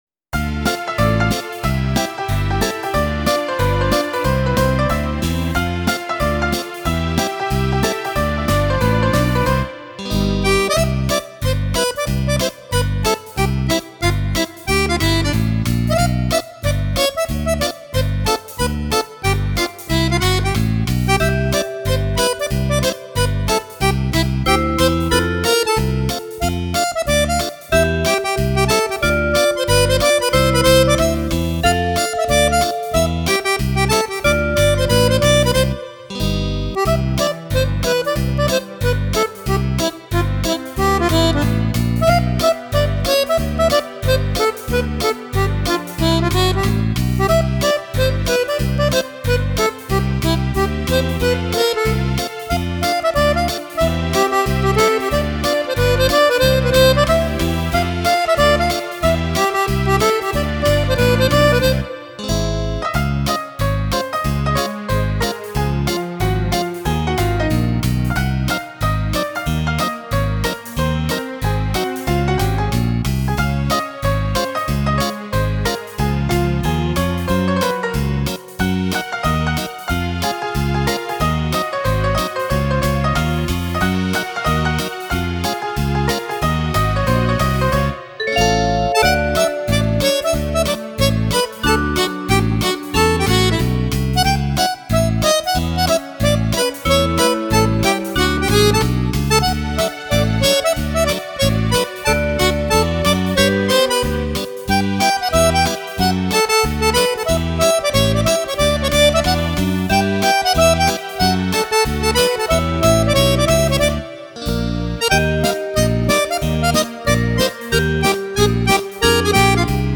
Fox
14 ballabili per Fisarmonica  di facile esecuzione.